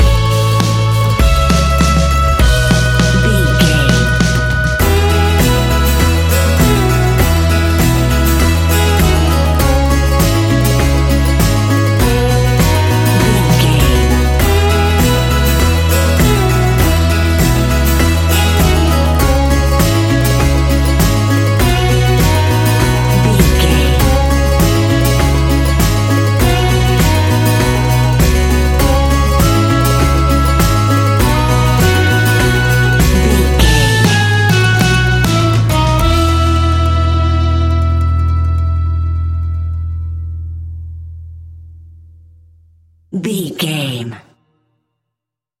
Uplifting
Ionian/Major
acoustic guitar
mandolin
double bass
accordion